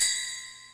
DrJingle.wav